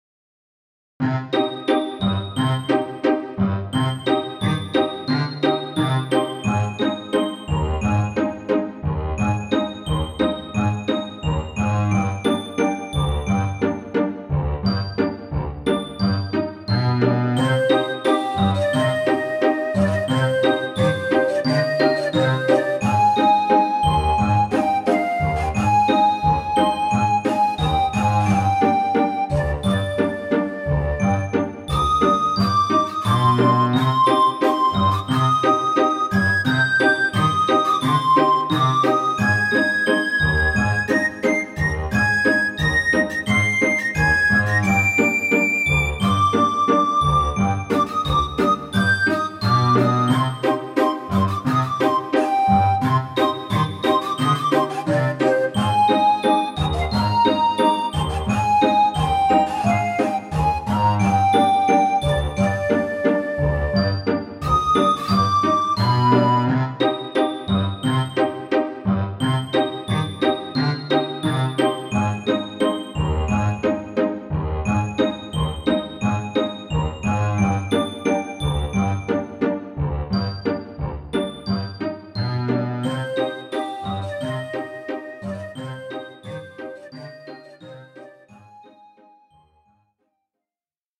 gothic, folk and rock
Recorded digitally, using a Sound Blaster Audigy 2 SE.